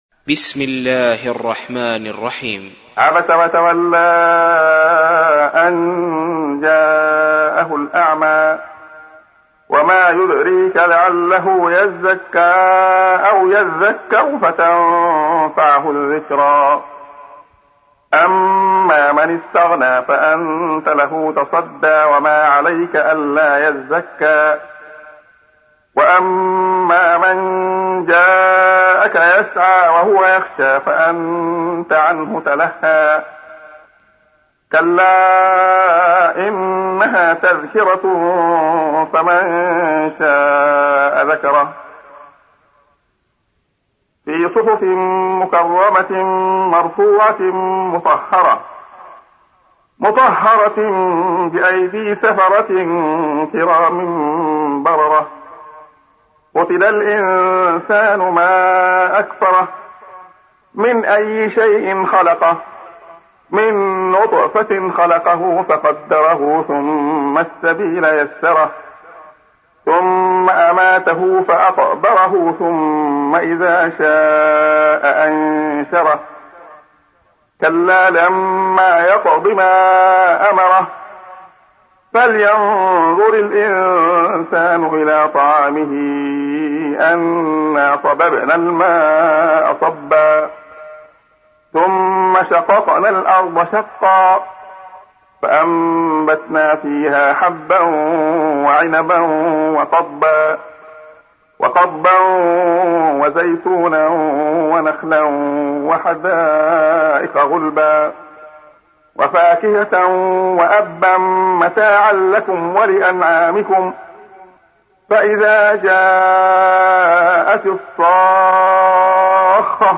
سُورَةُ عَبَسَ بصوت الشيخ عبدالله الخياط